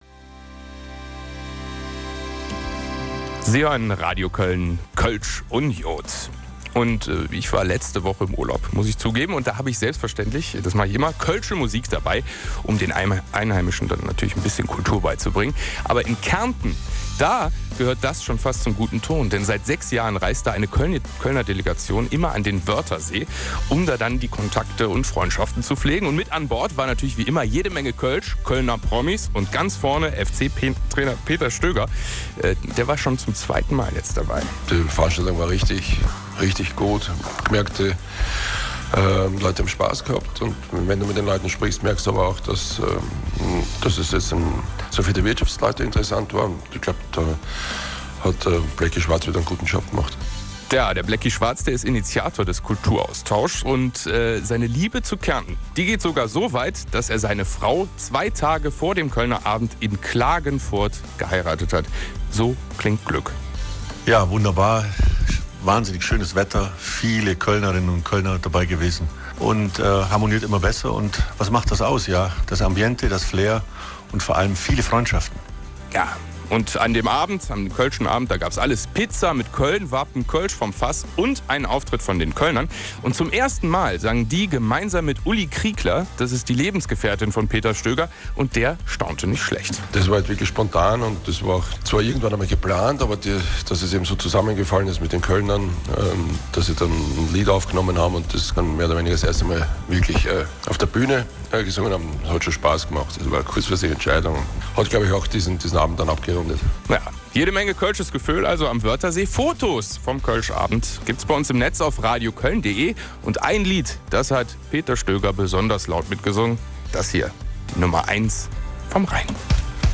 Wirtschaftsdialog 2016 Radiointerview
radiointerview _Wirtschaftsdialog_2016.mp3